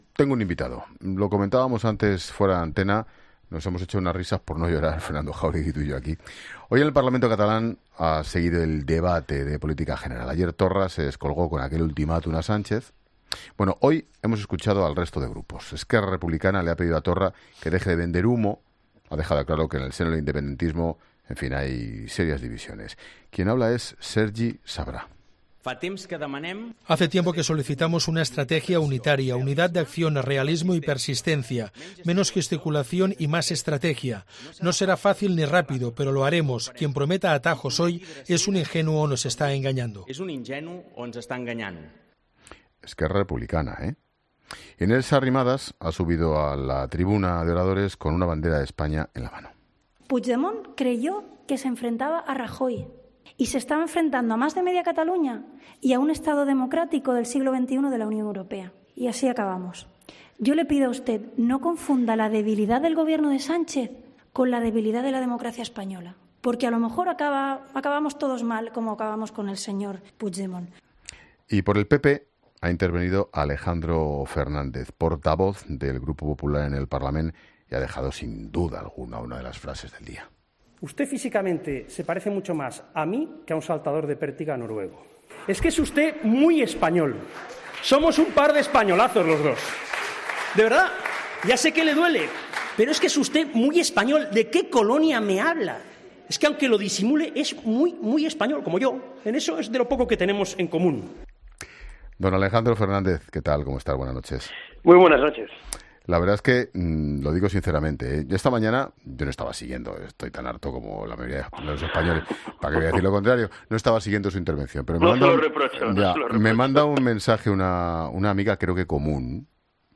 El diputado del PPC, Alberto Fernández, dice en 'La Linterna' que el presidente de la Generalitat es un "fanático"